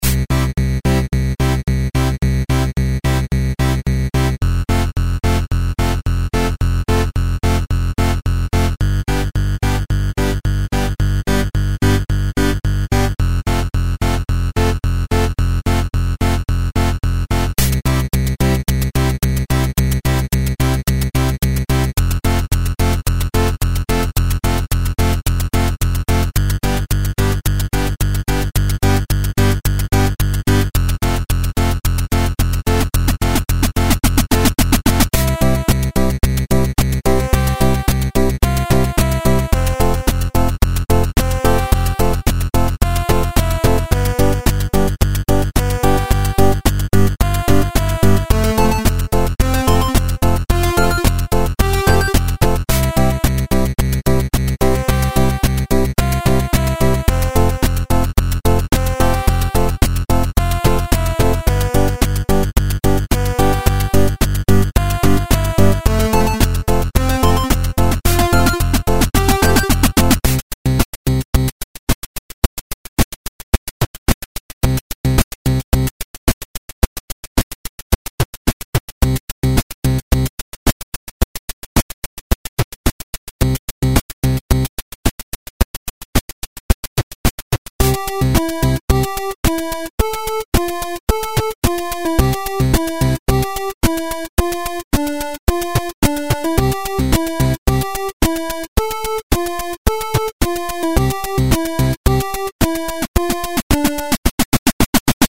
It is a beautiful sequence performed with 8-bit sounds.